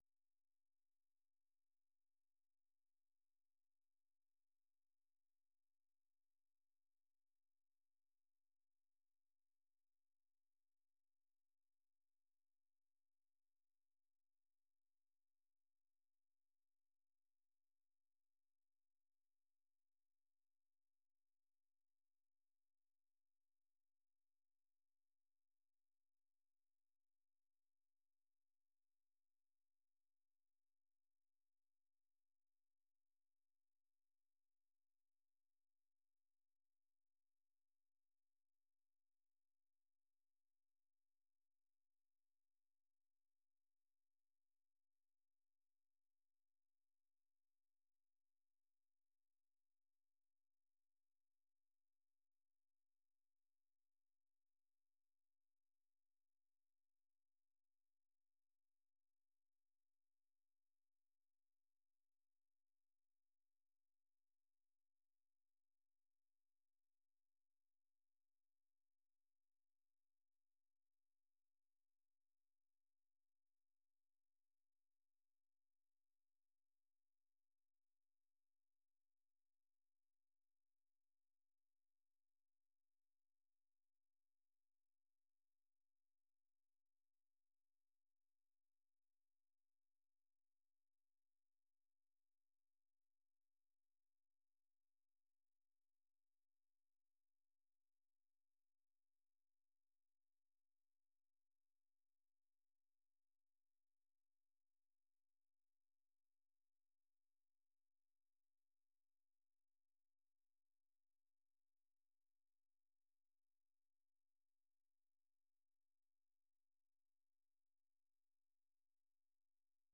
VOA 한국어 방송의 월요일 오전 프로그램 2부입니다. 한반도 시간 오전 5:00 부터 6:00 까지 방송됩니다.